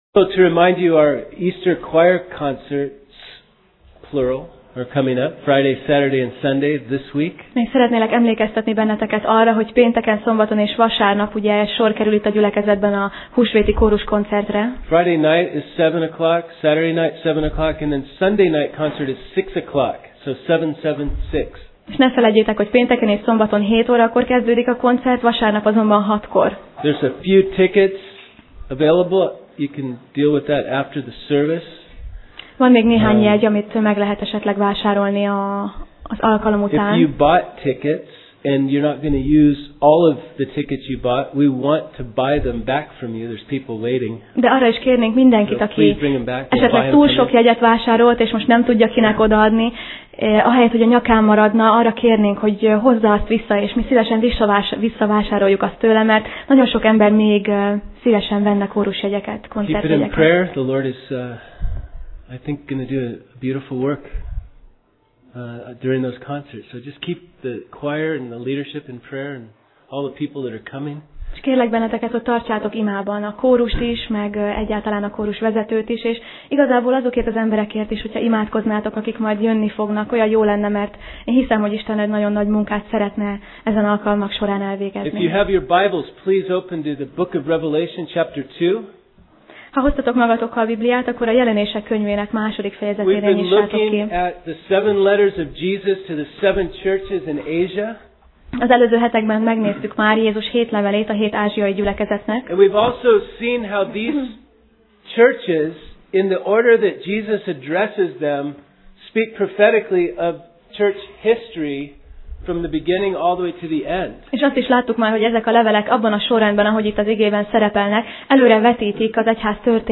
Passage: Jelenések (Revelation) 2:18-29 Alkalom: Vasárnap Reggel